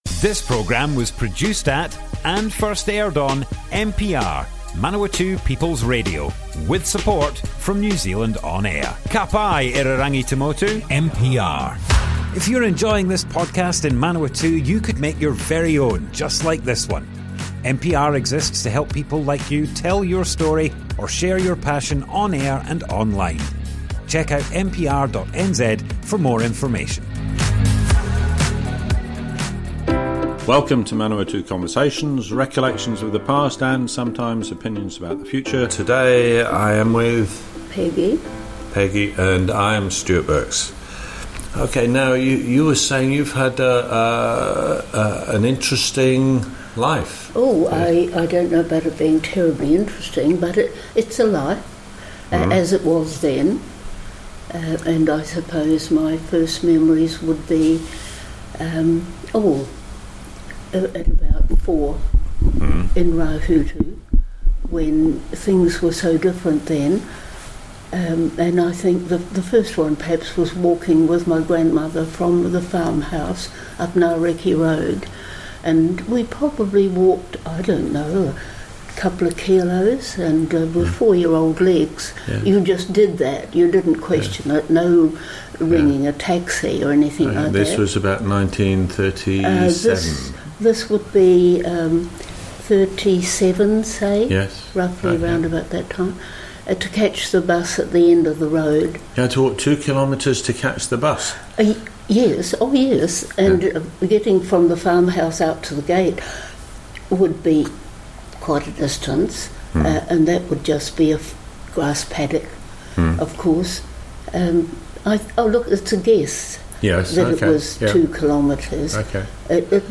Manawatu Conversations More Info → Description Broadcast on Manawatu People's Radio, 18th October 2022.
oral history